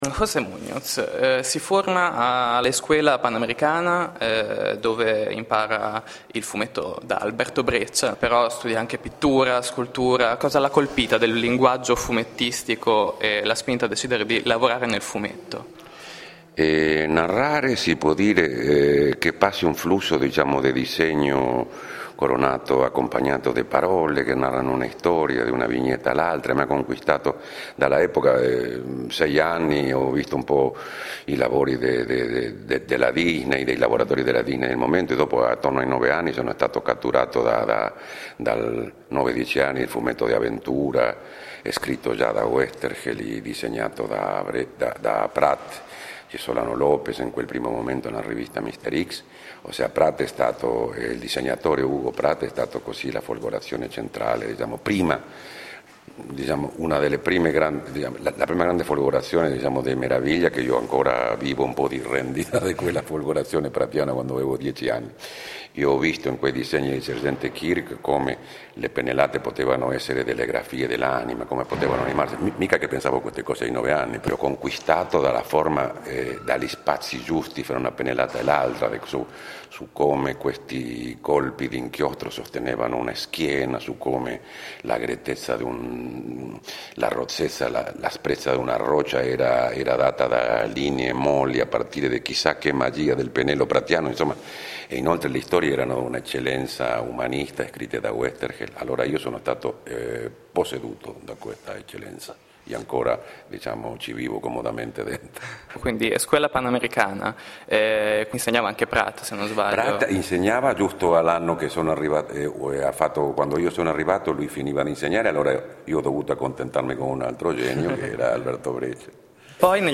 In occasione della quinta edizione di BilBOlbul, Flashfumetto ha intervistato il grande maestro del fumetto argentino José Muñoz, cui proprio nell'ambito di BilBOlbul è dedicata la mostra Come la vita, visitabile al Museo Archeologico di Bologna fino al 10 aprile 2011.